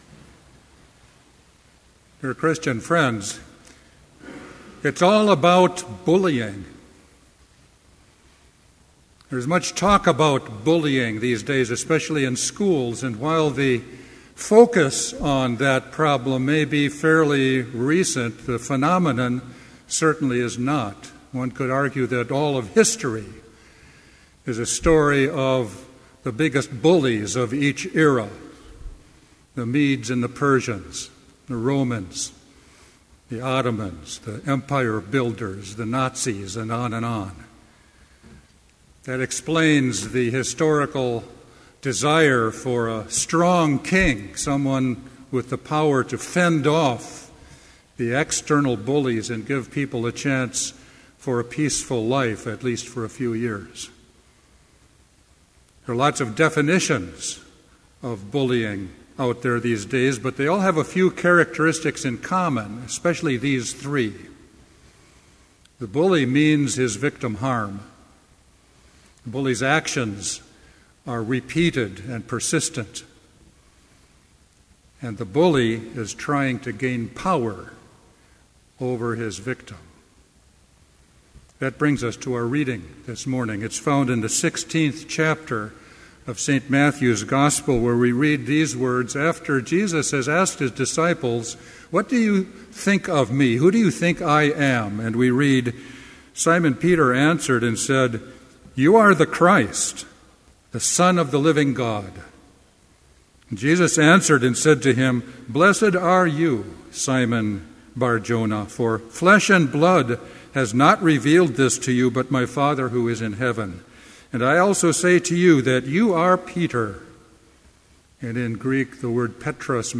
Complete Service
Order of Service
• Hymn 259, vv. 1 & 2, The Kingdom Satan Founded
• Homily
This Chapel Service was held in Trinity Chapel at Bethany Lutheran College on Tuesday, March 5, 2013, at 10 a.m. Page and hymn numbers are from the Evangelical Lutheran Hymnary.